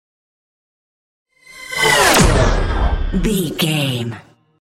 Whoosh speed with shot
Sound Effects
tension
whoosh
shoot